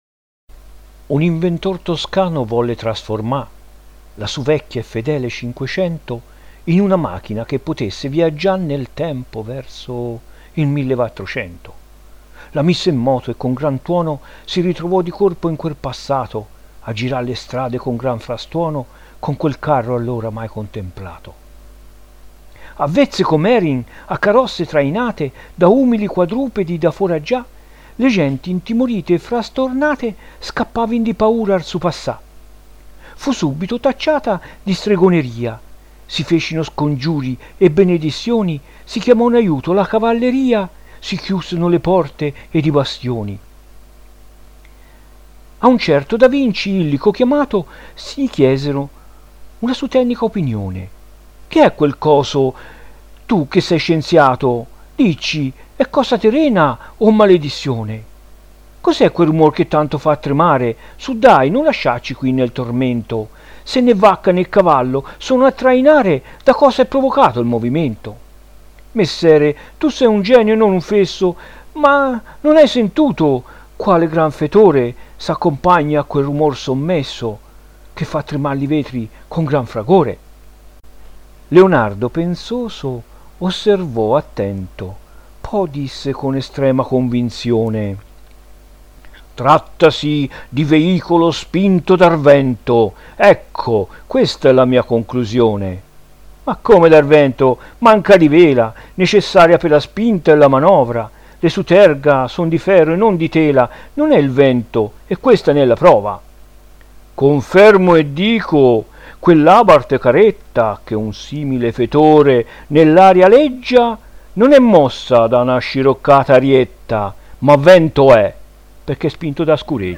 Concorso di Poesia
Sezione Vernacolo